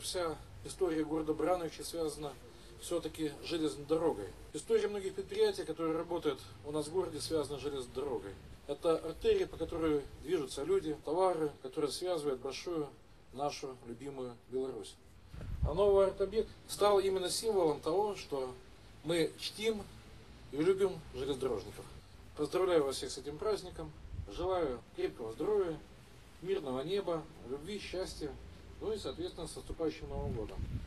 В честь этого знаменательного события   на центральной площади города был открыт арт-объект.
Стальная магистраль- это надежный перевозчик пассажиров, грузов. Это артерия, которая связывает нашу родную Беларусь, — отметил председатель горисполкома Михаил Баценко.